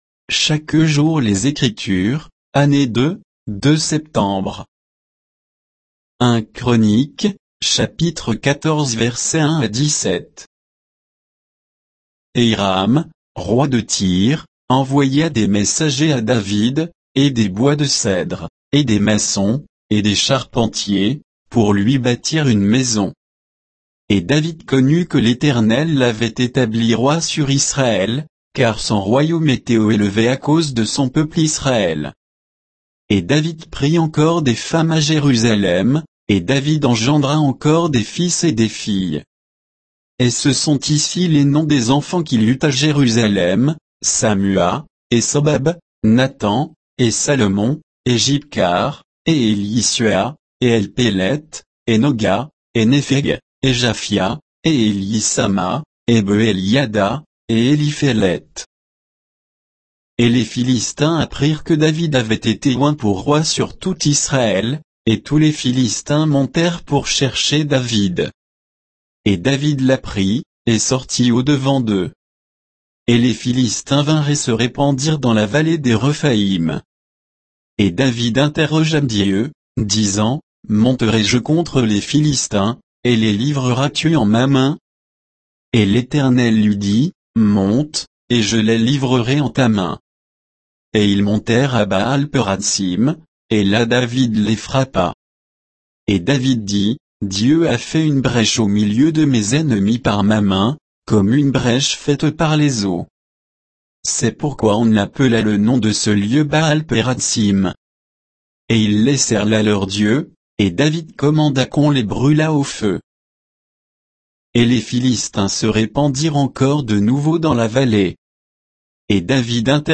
Méditation quoditienne de Chaque jour les Écritures sur 1 Chroniques 14